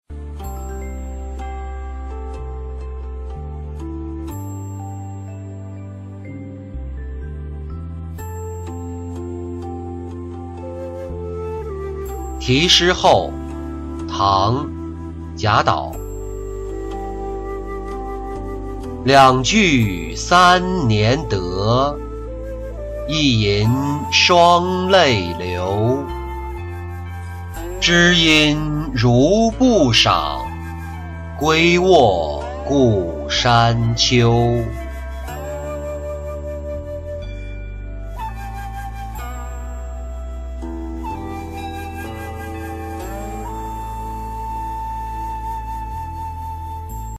题诗后-音频朗读